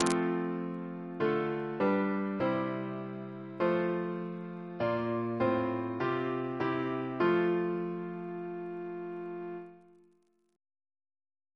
Single chant in F Composer: Benjamin Lamb (b.fl.1715) Reference psalters: OCB: 53